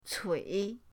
cui3.mp3